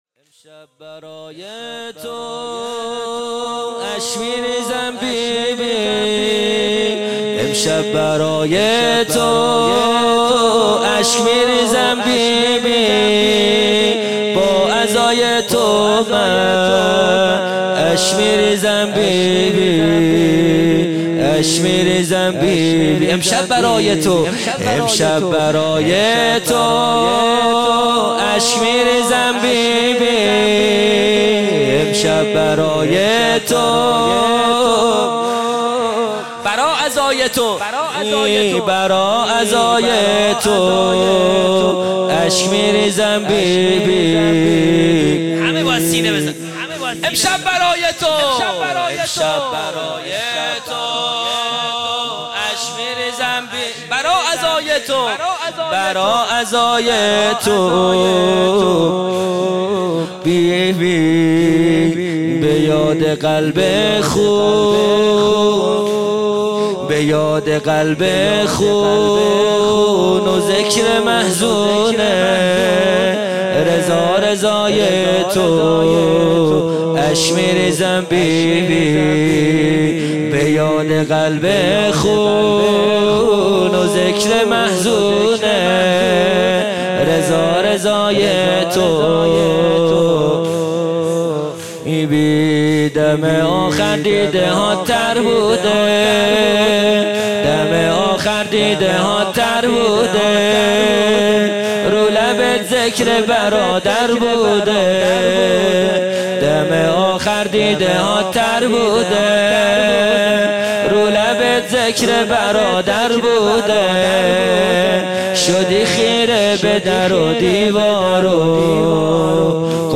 خیمه گاه - هیئت بچه های فاطمه (س) - زمینه | امشب برای تو اشک میریزم بی بی | 27 آبان 1400
جلسه هفتگی | وفات حضرت معصومه(س)